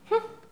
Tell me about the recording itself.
Les sons ont été découpés en morceaux exploitables. 2017-04-10 17:58:57 +02:00 92 KiB Raw History